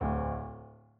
sfx_猪头走路1.wav